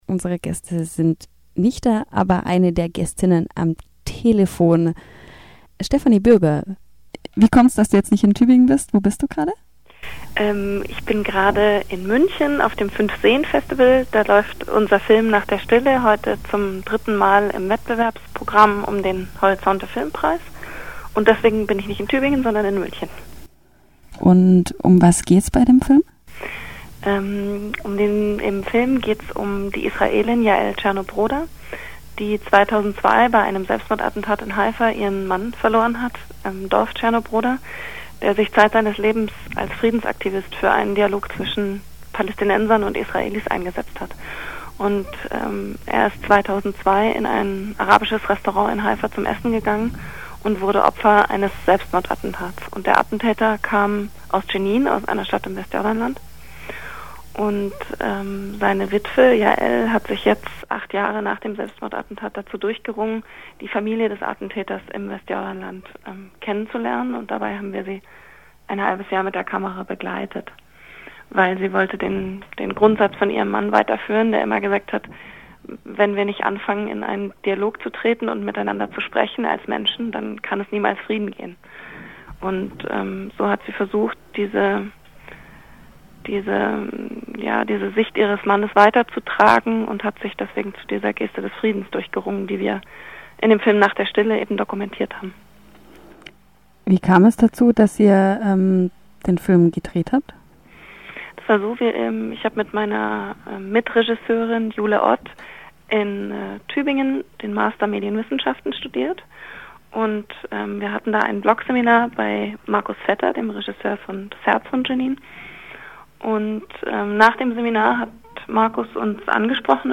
Am Freitag, den 5. August telefonierten wir in der Gästestunde